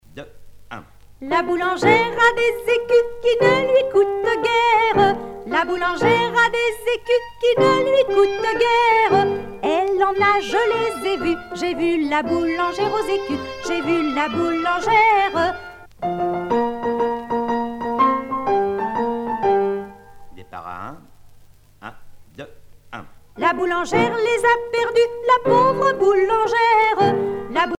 Rondes enfantines à baisers ou mariages
danse : ronde
Pièce musicale éditée